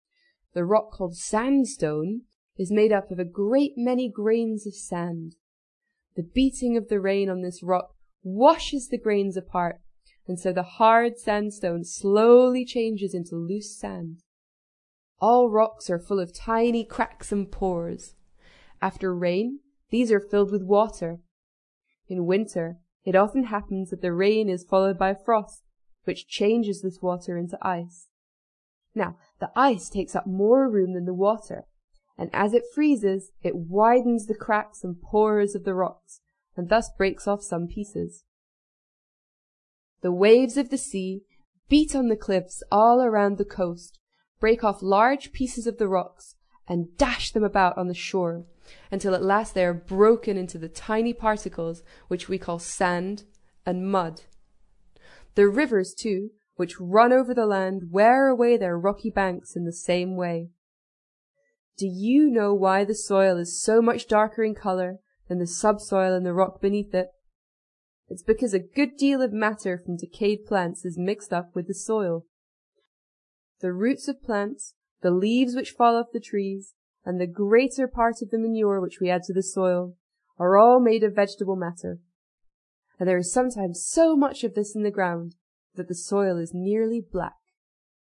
在线英语听力室英国学生科学读本 第80期:我们脚下的土地(2)的听力文件下载,《英国学生科学读本》讲述大自然中的动物、植物等广博的科学知识，犹如一部万物简史。在线英语听力室提供配套英文朗读与双语字幕，帮助读者全面提升英语阅读水平。